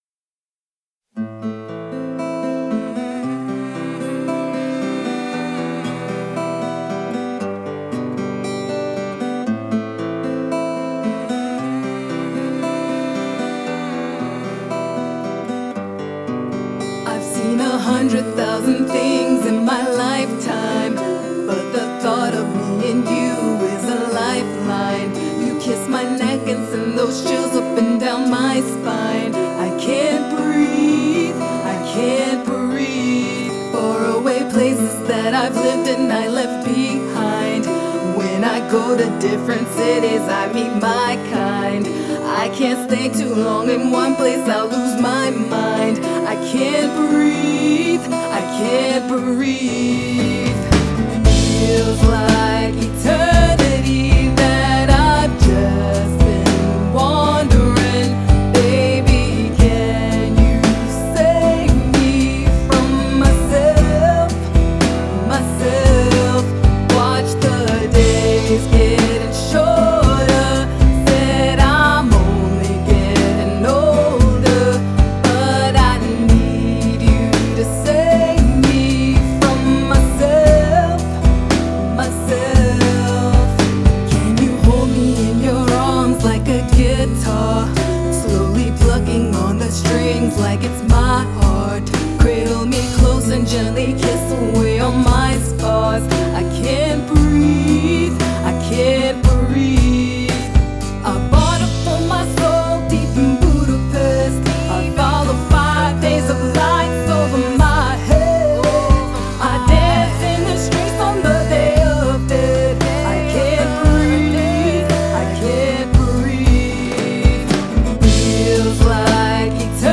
Guitar
Vocals
Bass
Drums